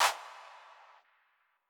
Light Clap